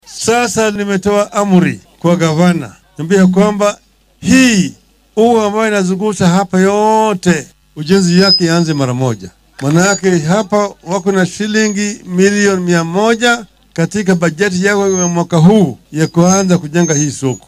Raila oo arrimahan ka hadlayay ayaa yiri.